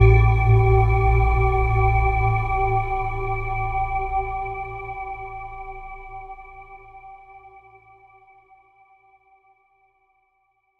Chords_A_02.wav